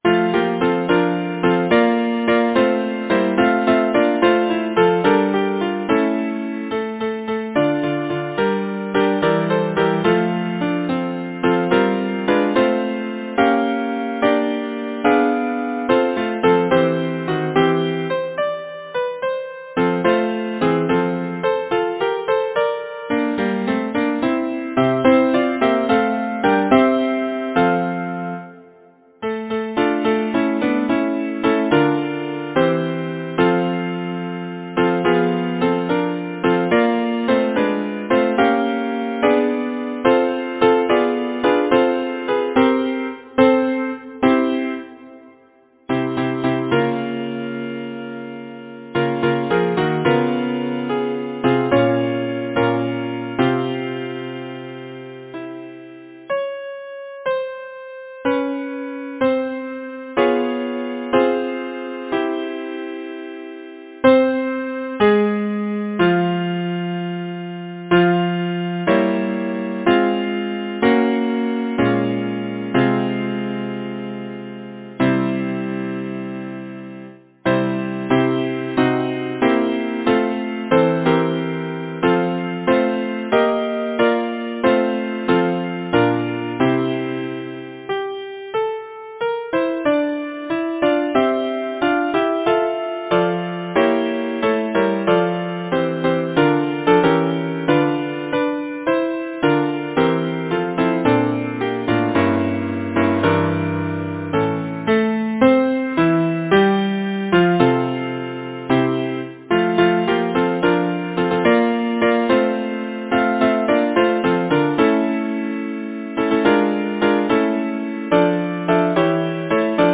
Title: Little Bo-Peep Composer: Henry Dancey Lyricist: Number of voices: 4vv Voicing: SATB Genre: Secular, Partsong, Nursery rhyme, Humorous song
Language: English Instruments: A cappella